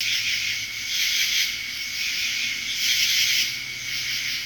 DAY INSEC03L.wav